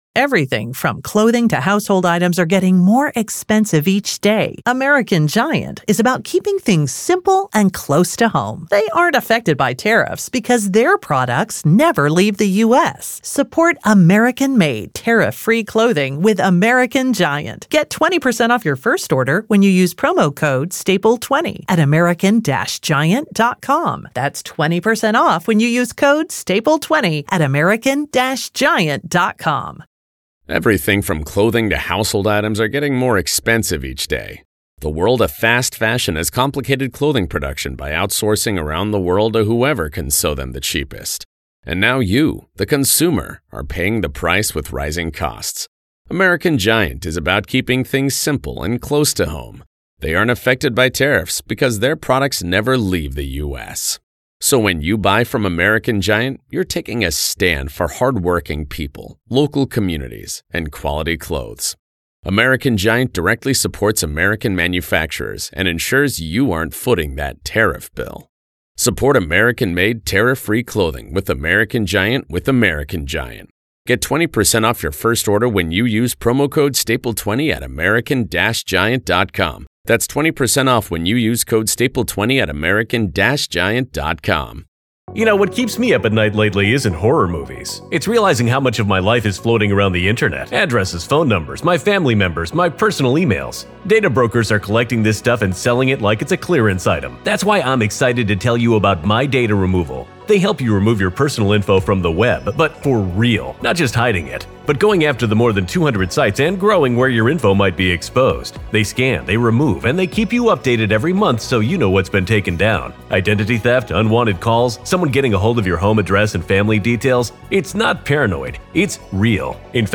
The panel debates the steep uphill battle of an insanity defense, especially with video showing Stines acting methodically in the moments before pulling the trigger. They also analyze the difficulty of investigating corruption in a small community where silence, retaliation, and rumor rule.